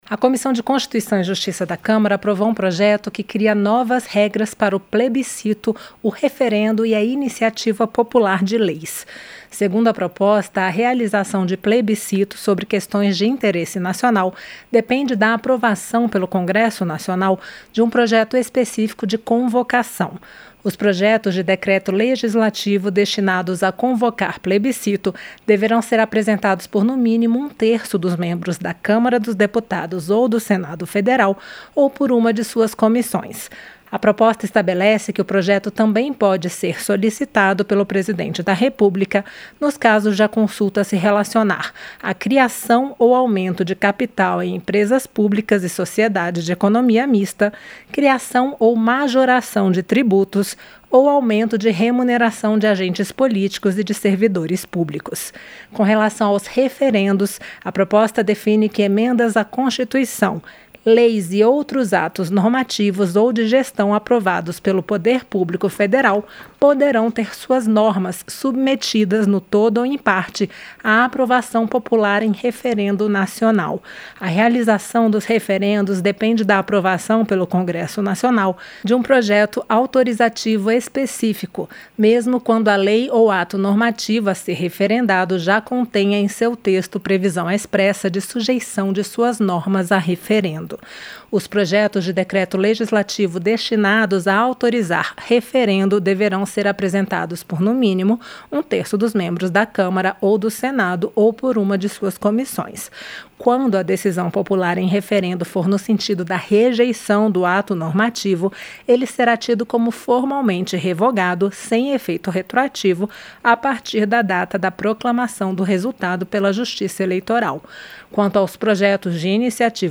COMISSÃO DE CONSTITUIÇÃO E JUSTIÇA DA CÂMARA APROVA NOVAS REGRAS PARA PLEBISCITO, REFERENDO E PROJETOS DE INICIATIVA POPULAR. ENTENDA NA REPORTAGEM